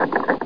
00857_Sound_turn.mp3